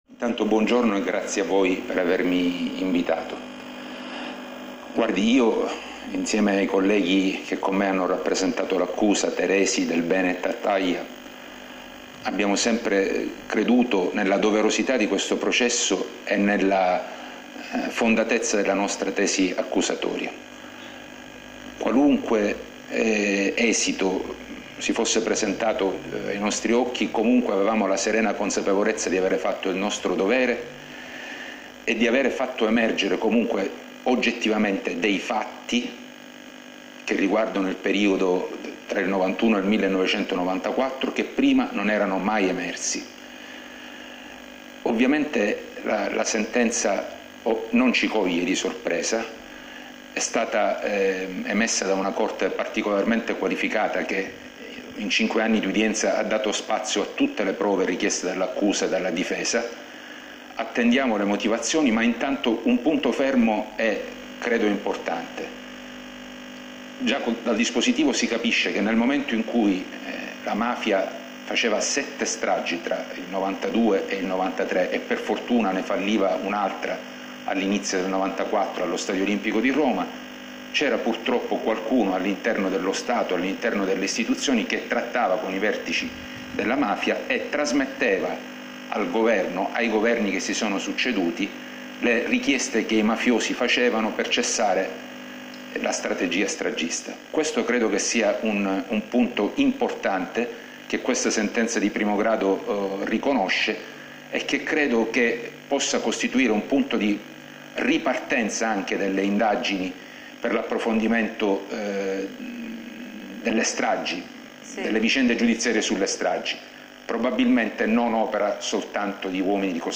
TRA LO STATO E LA MAFIA, EMESSA NEI GIORNI SCORSI A PALERMO-OSPITE DI LUCIA ANNUNZIATA, DOMENICA SU RAI3